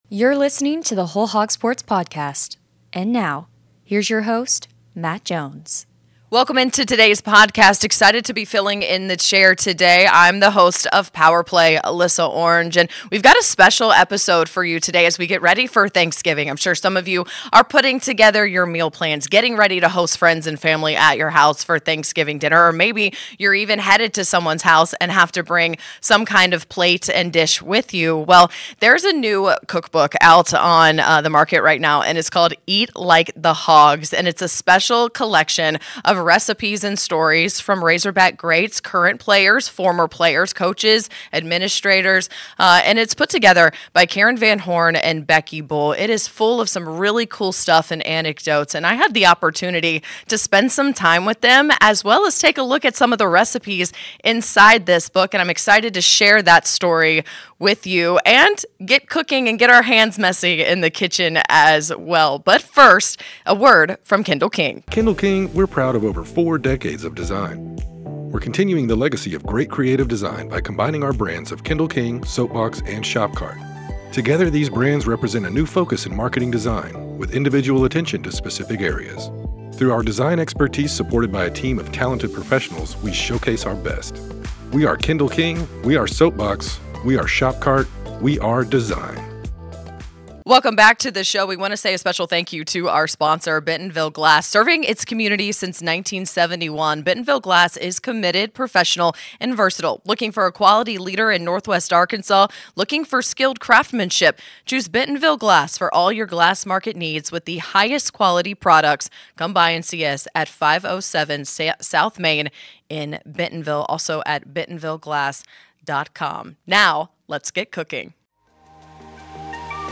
in the kitchen to whip up a chocolate chip cookie recipe